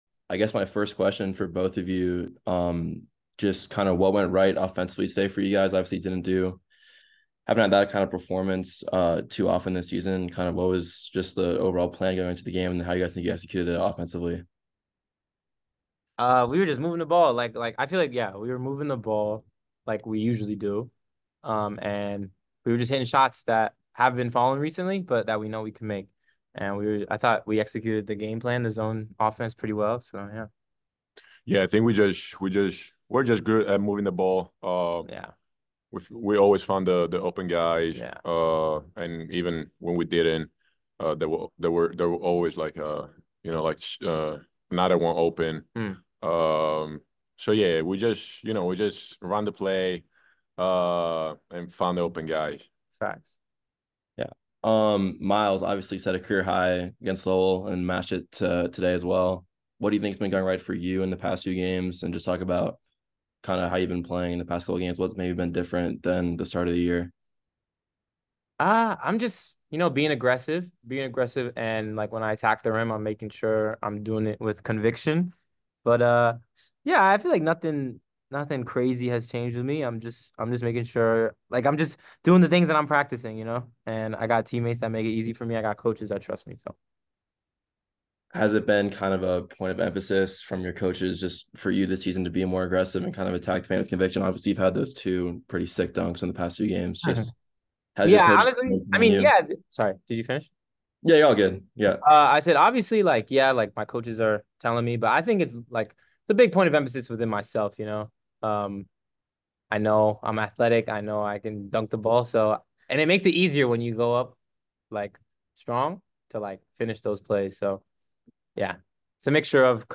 Merrimack Postgame Press Conference